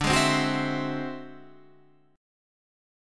D9b5 Chord
Listen to D9b5 strummed